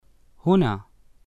[hunaa]